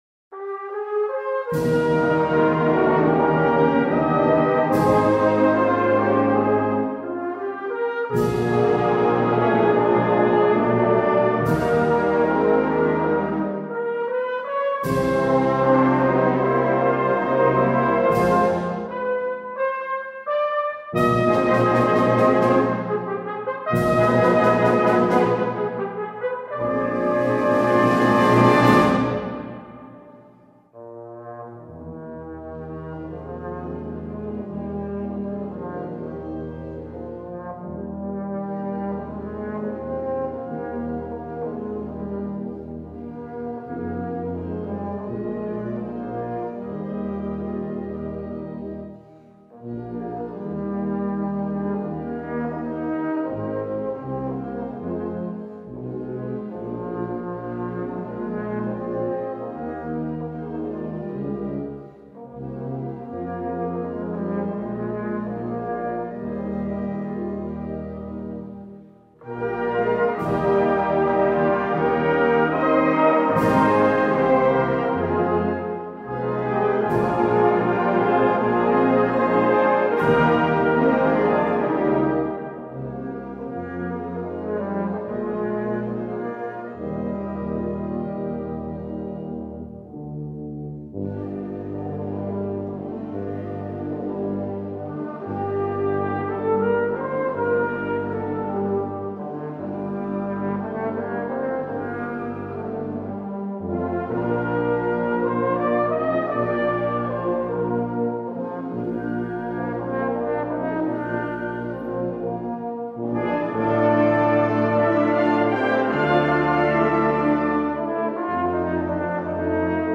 Solo für Tenorhorn/Bariton
Blasorchester